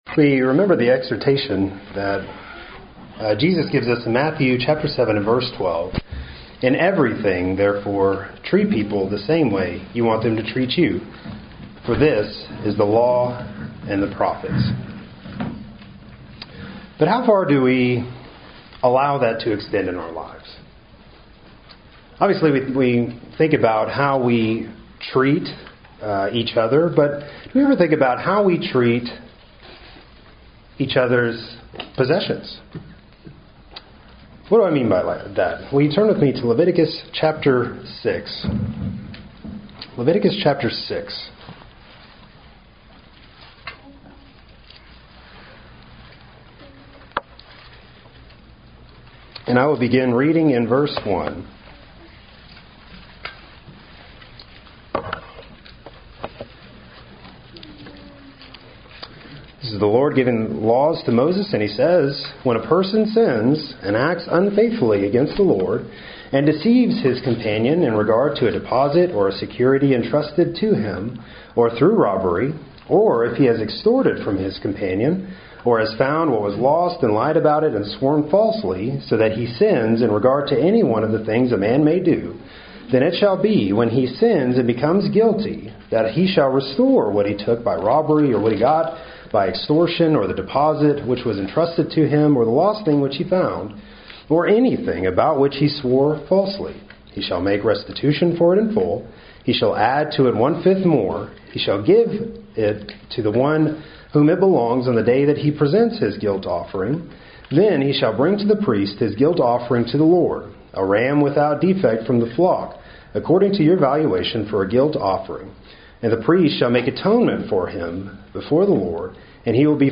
Happy Church of Christ Listen to Sermons